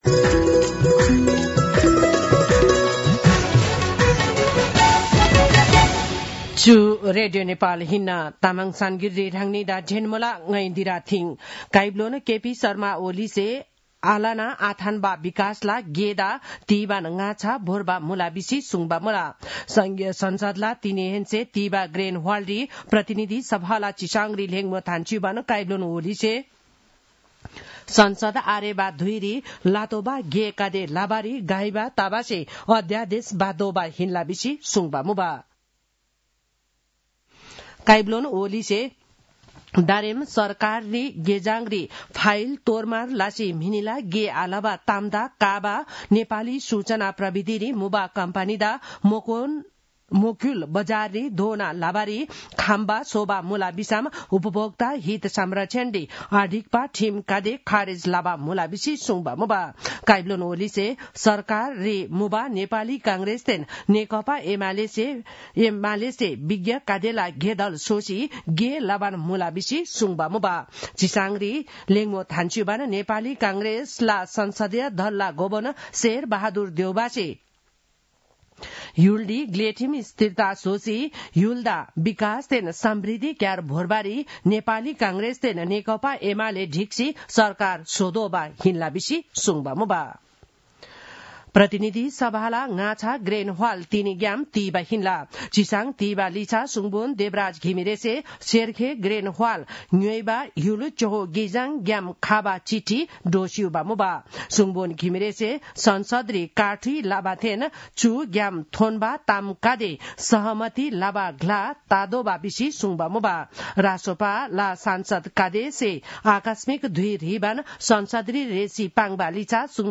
तामाङ भाषाको समाचार : १९ माघ , २०८१